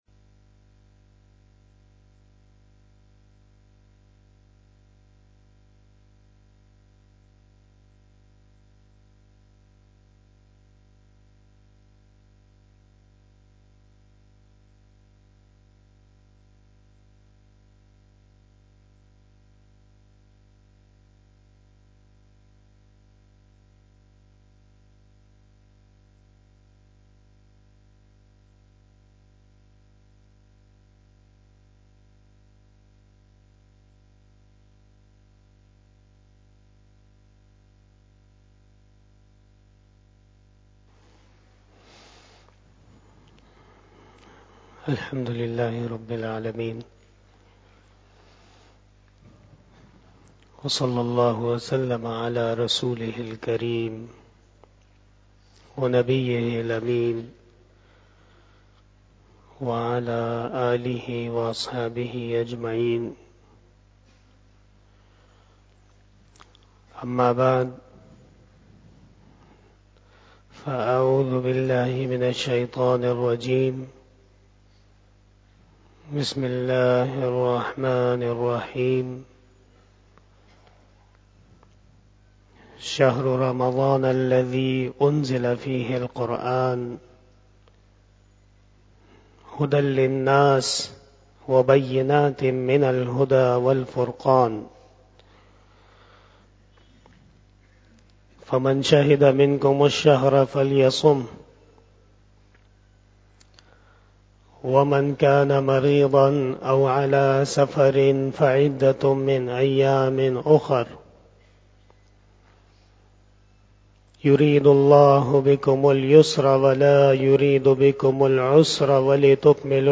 بیان شب جمعۃ المبارک08 دسمبر2022 بمطابق 14 جمادی الاولی 1444ھ